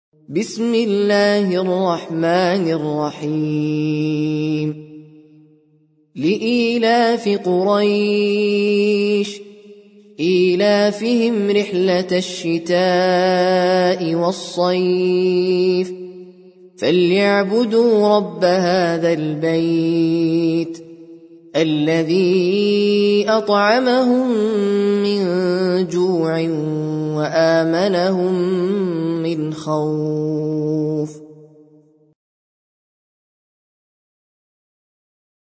Sûrat Quraish - Al-Mus'haf Al-Murattal (Narrated by Hafs from 'Aasem)
very high quality